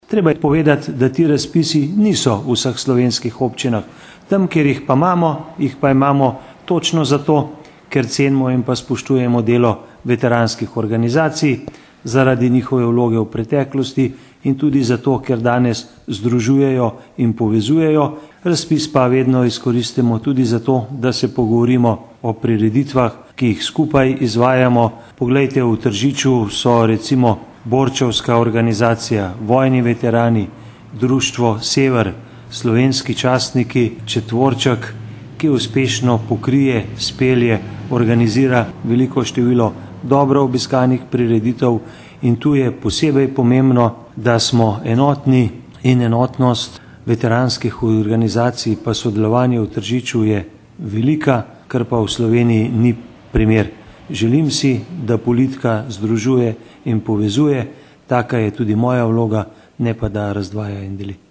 izjava_zupanobcinetrzicmag.borutsajovicoveteranskihorganizacijah.mp3 (1,5MB)